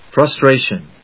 音節frus・tra・tion 発音記号・読み方
/frʌstréɪʃən(米国英語), frʌˈstreɪʃʌn(英国英語)/